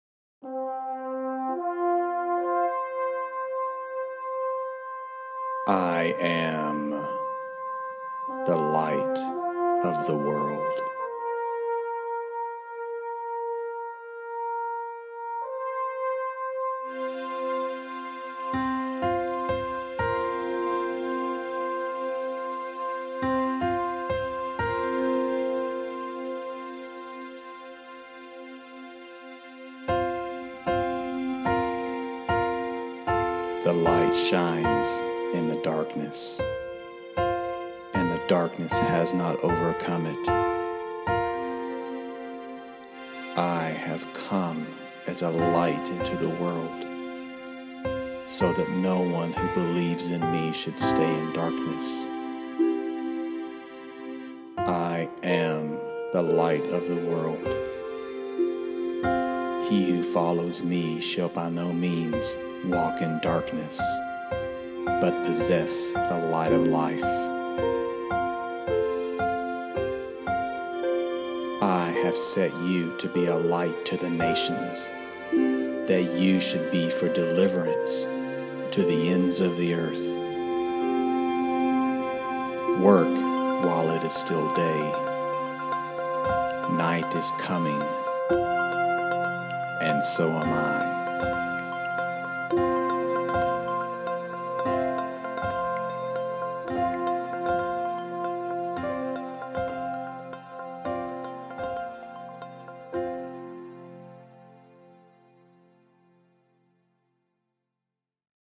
Listen to the words of Messiah, the Light of the World, recorded against the backdrop of a new inspiring instrumental.